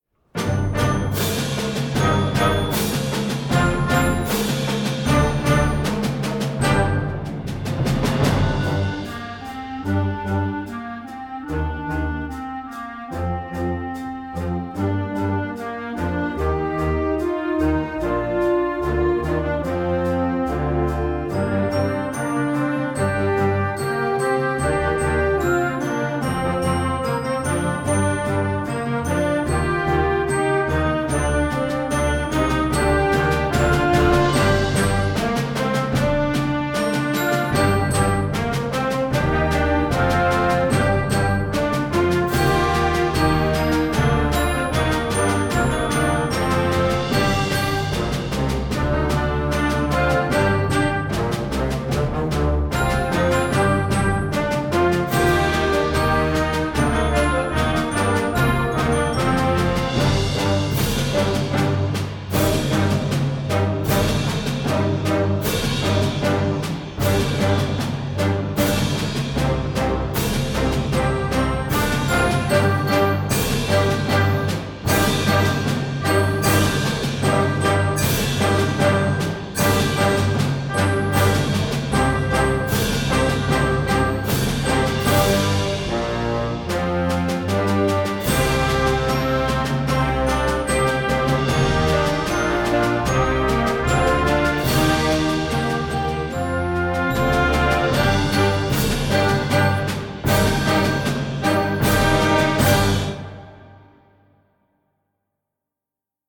Konzertwerk für Jugendblasorchester
Besetzung: Blasorchester